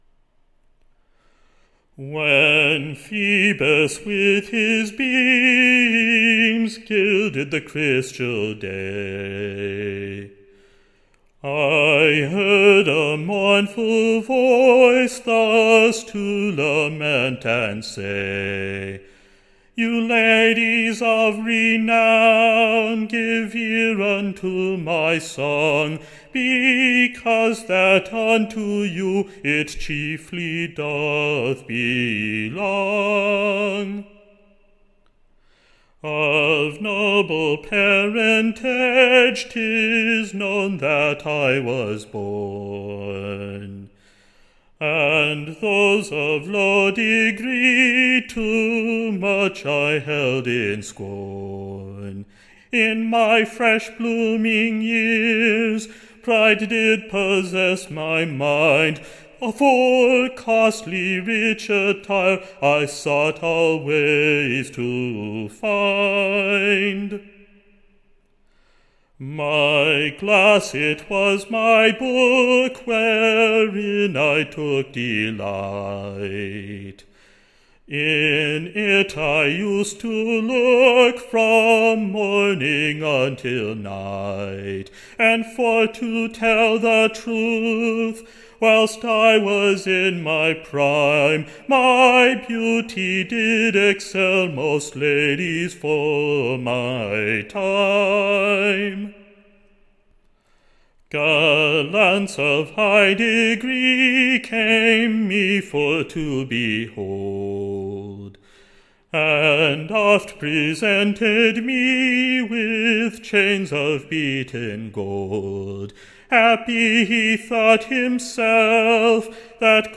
Recording Information Ballad Title The Court=Miss Converted: / OR, A Looking Glass fo Ladies.